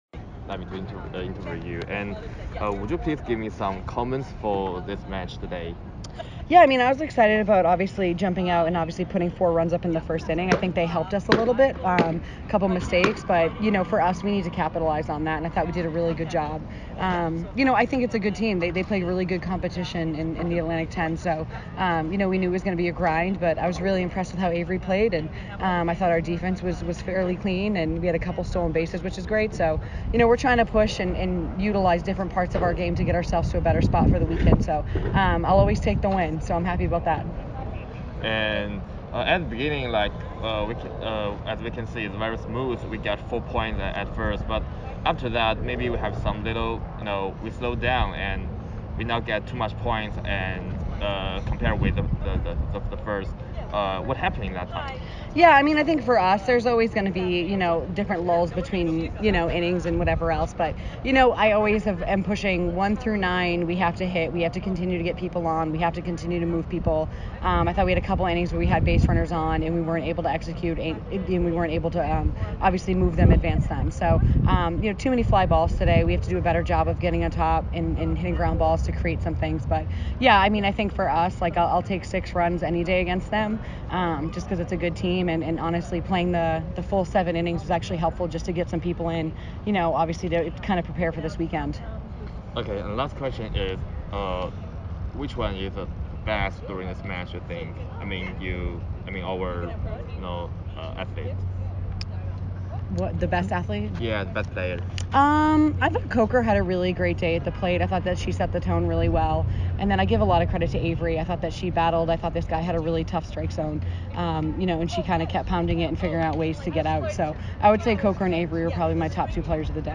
UMass Softball Postgame Interview